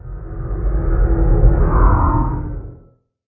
minecraft / sounds / mob / guardian / elder_idle4.ogg
elder_idle4.ogg